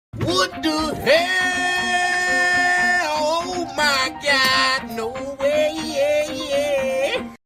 what the heeeeeeellll oh my god no wayayay Meme Sound Effect
This sound is perfect for adding humor, surprise, or dramatic timing to your content.
what the heeeeeeellll oh my god no wayayay.mp3